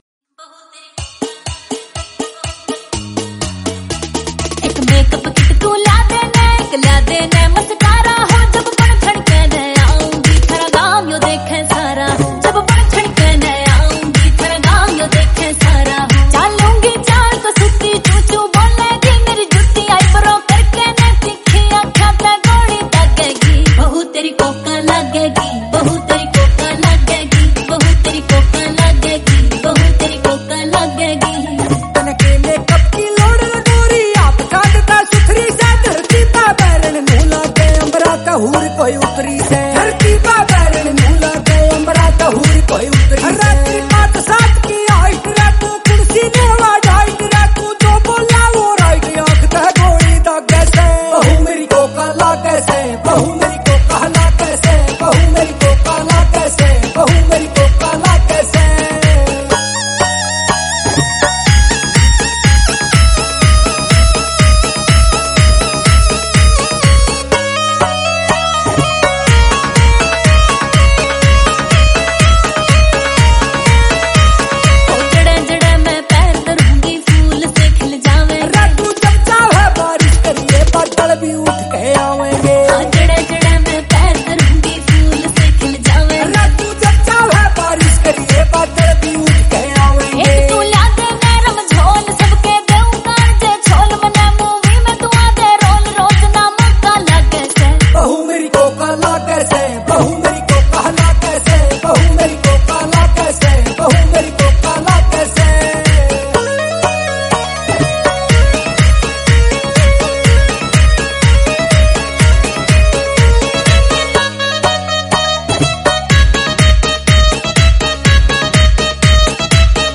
Category: Haryanvi Songs